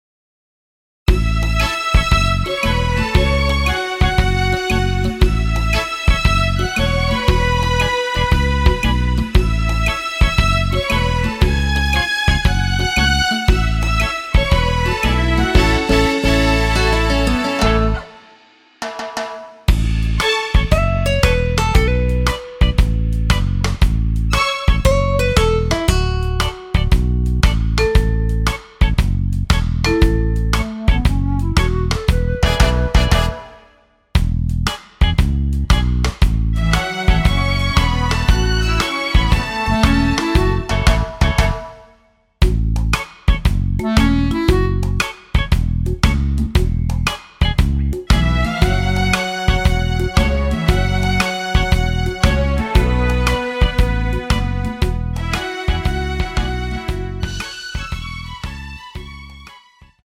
원키에서(-7)내린 남성분이 부르실수 있는 키의 MR입니다.
앞부분30초, 뒷부분30초씩 편집해서 올려 드리고 있습니다.